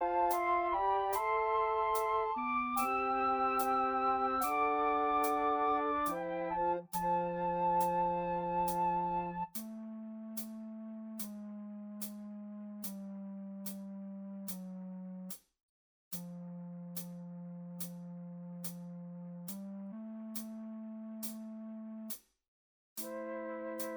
Minus Acoustic Guitars Rock 8:06 Buy £1.50